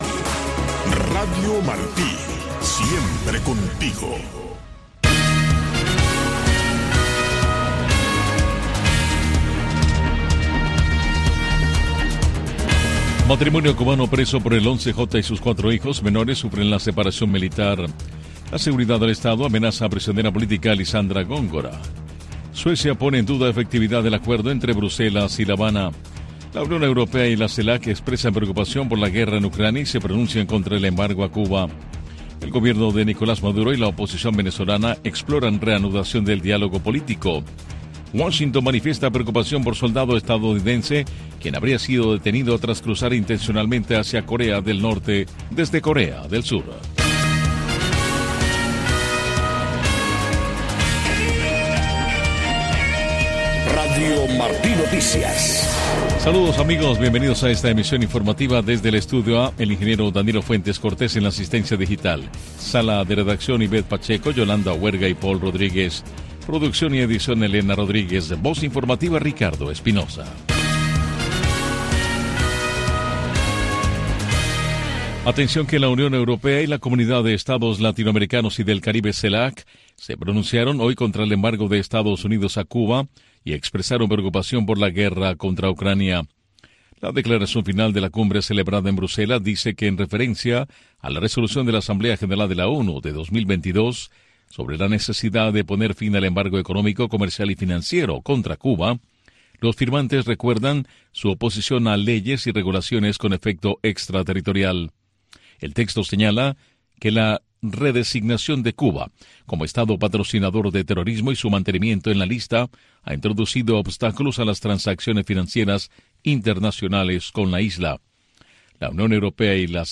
Noticiero de Radio Martí 3:00 PM